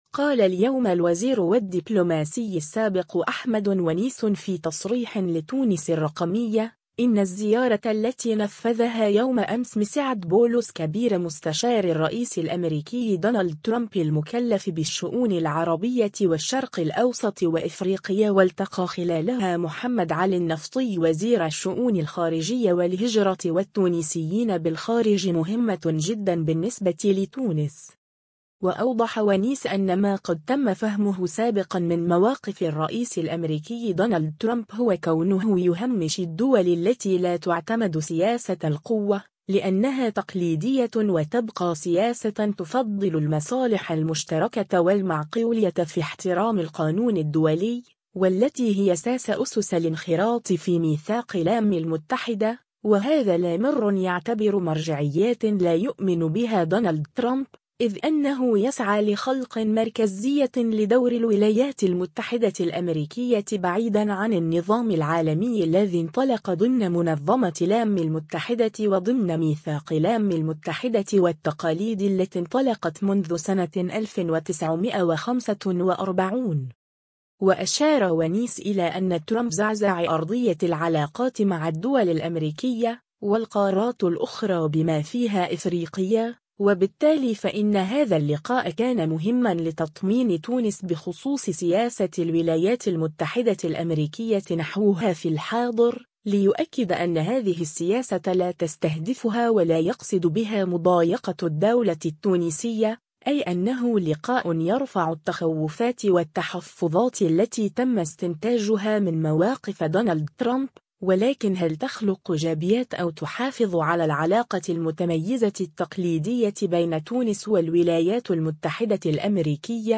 قال اليوم الوزير و الدّبلوماسي السابق أحمد ونيس في تصريح لتونس الرّقمية، إنّ الزّيارة التي نفّذها يوم أمس مسعد بولس كبير مستشاري الرّئيس الأمريكي دونالد ترامب المكلّف بالشّؤون العربية والشّرق الأوسط وإفريقيا والتقى خلالها محمد علي النّفطي وزير الشّؤون الخارجية و الهجرة و التونسيين بالخارج مهمّة جدا بالنّسبة لتونس.